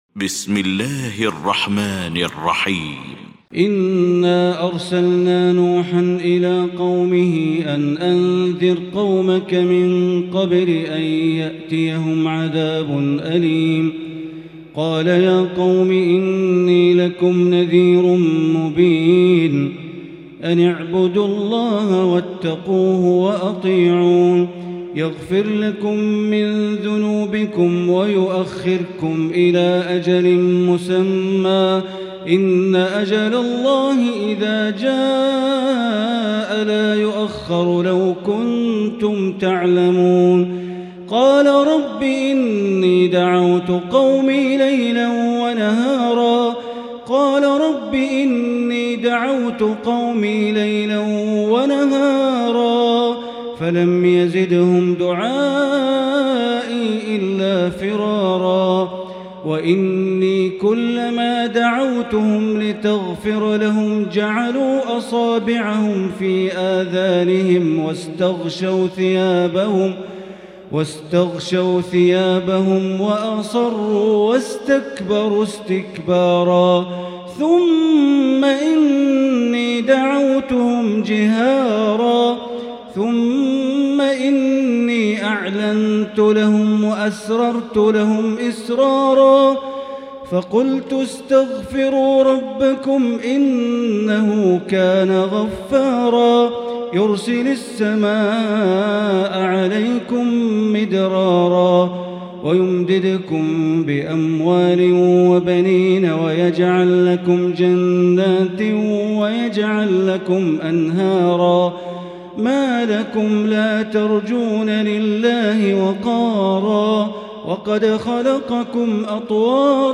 المكان: المسجد الحرام الشيخ: معالي الشيخ أ.د. بندر بليلة معالي الشيخ أ.د. بندر بليلة نوح